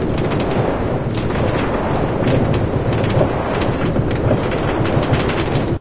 minecart